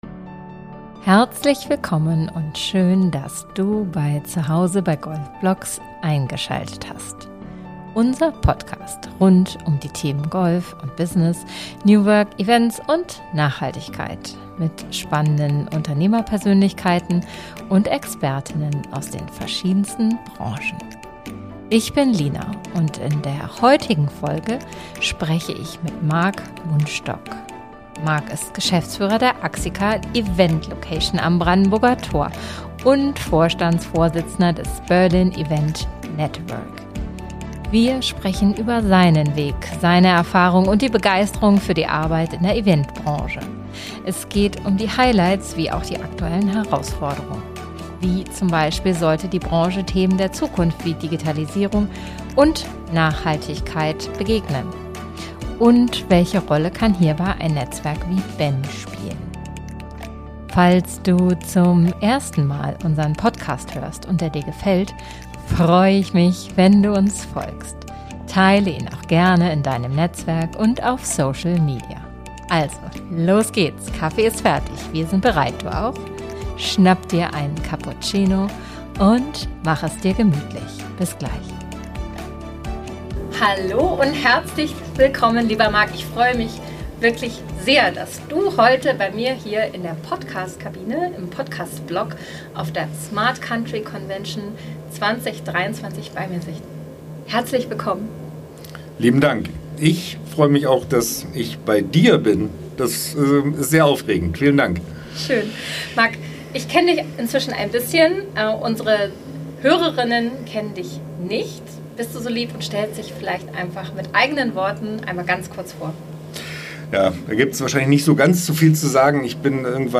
Wir sitzen in unserem BLOCK auf der Smart Country Convention und sprechen über seinen Weg, seine Erfahrung und die Begeisterung für die Arbeit in der Event-Branche. Es geht um die Highlights wie auch die aktuellen Herausforderungen: Wie sollte die Branche Themen der Zukunft wie Digitalisierung und Nachhaltigkeit begegnen?